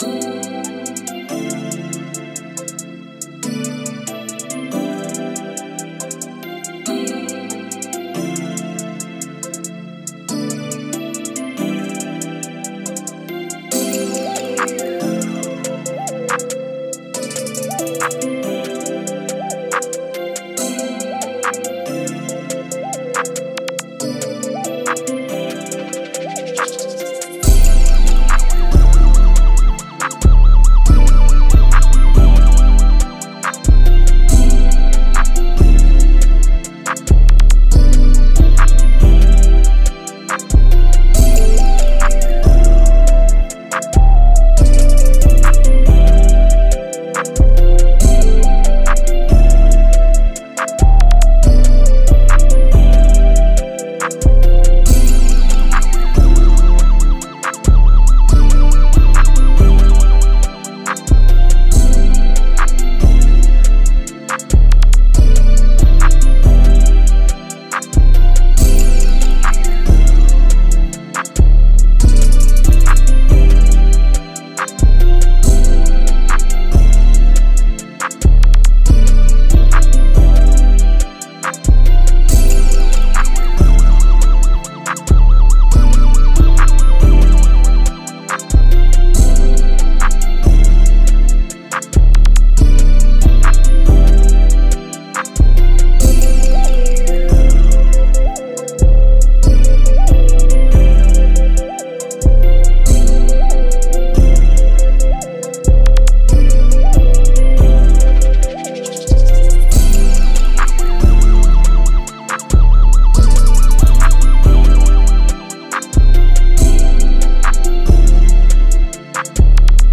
Key:Amin